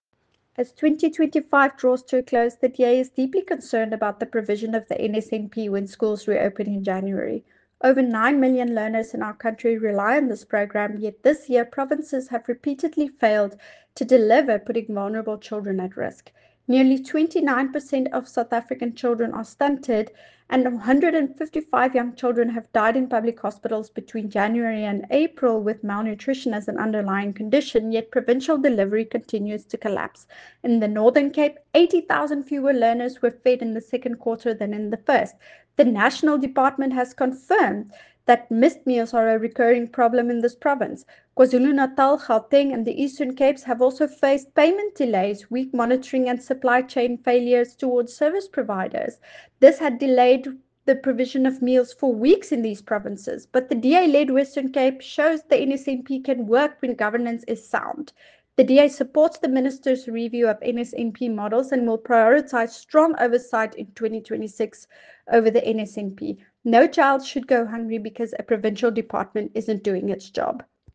Afrikaans soundbite by Ciska Jordaan MP.